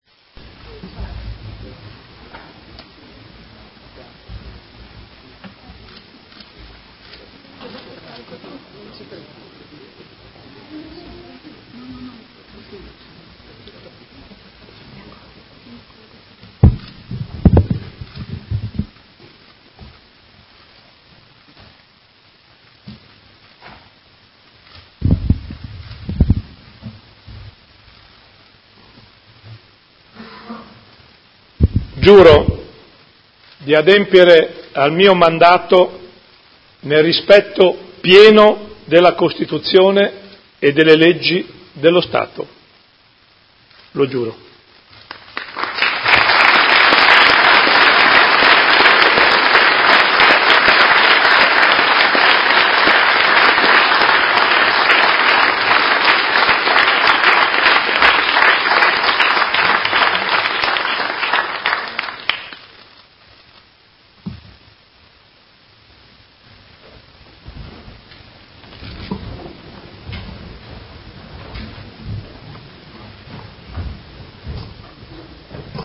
Seduta del 13/06/2019 Giuramento.